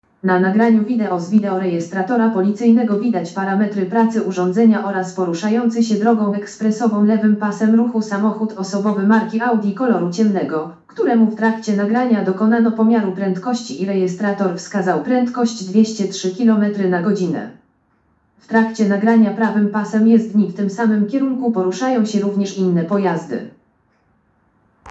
Nagranie audio Audideskrypcja_filmu_Nagranie_z_videorejestratora.m4a